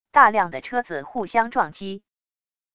大量的车子互相撞击wav音频